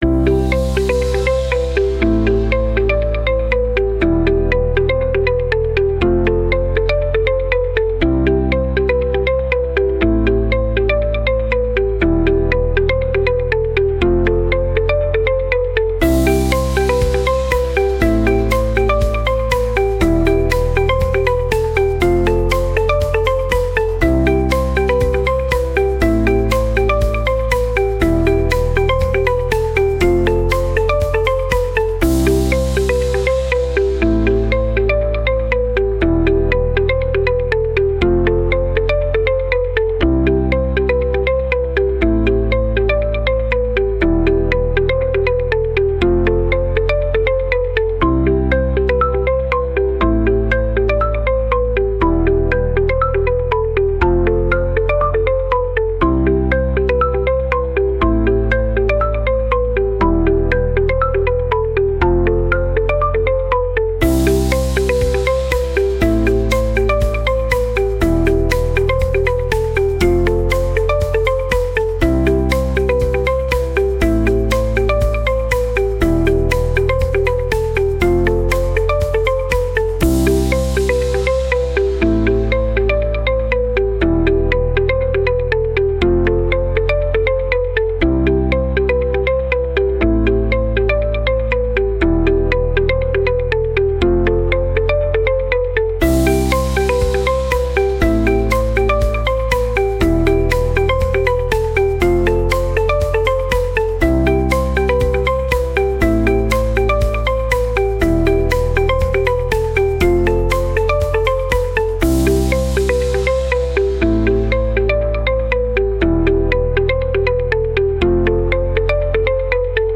• Категория: Детские песни / Музыка детям 🎵